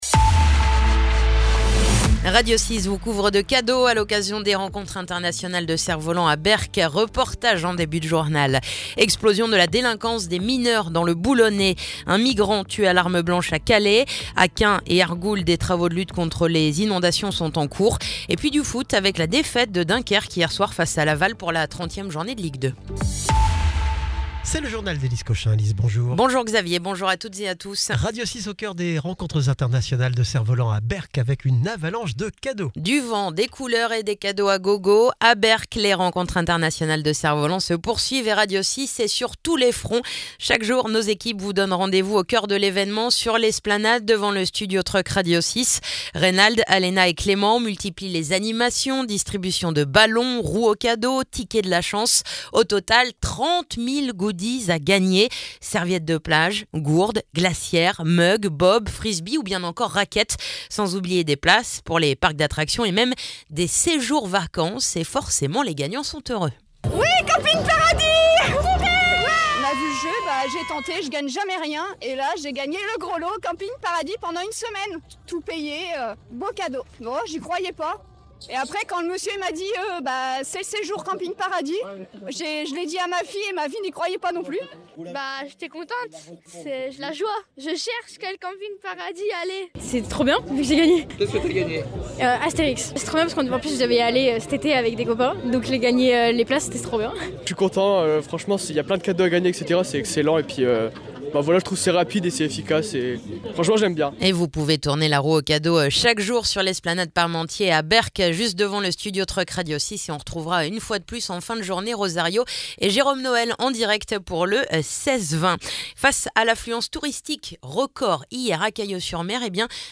Le journal du mardi 21 avril